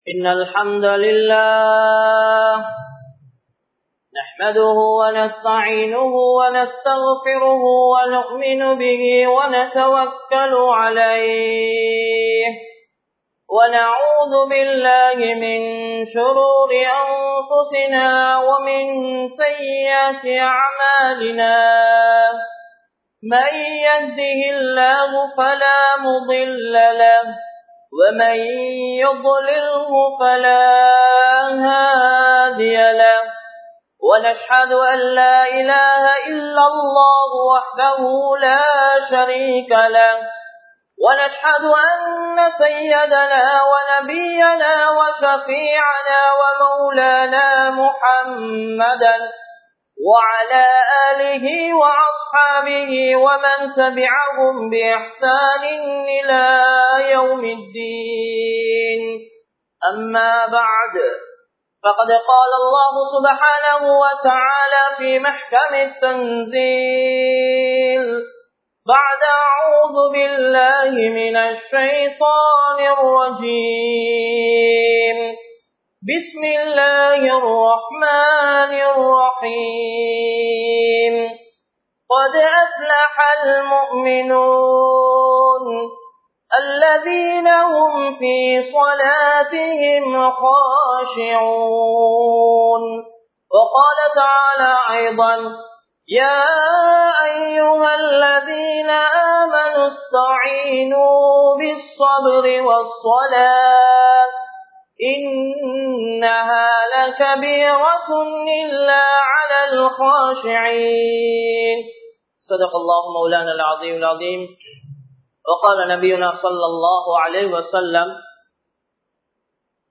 Tholuhaien Perumathi (தொழுகையின் பெறுமதி) | Audio Bayans | All Ceylon Muslim Youth Community | Addalaichenai
Gothatuwa, Jumua Masjidh